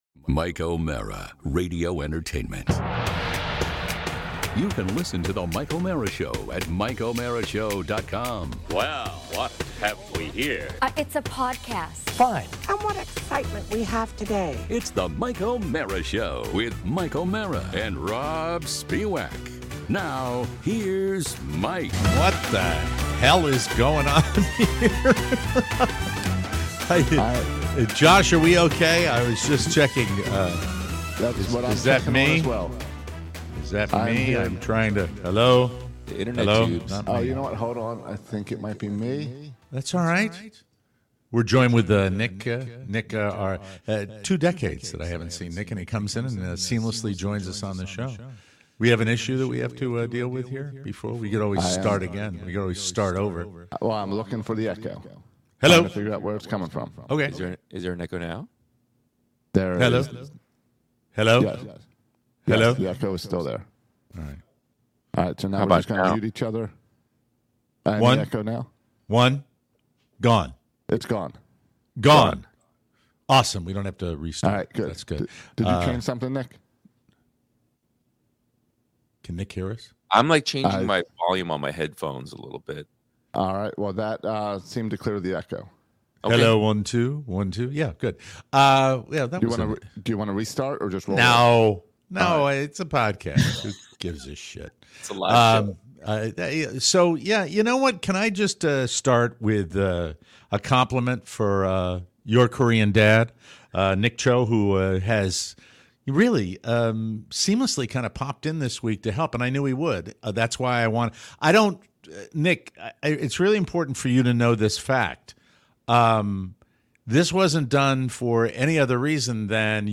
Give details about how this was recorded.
Despite an echo problem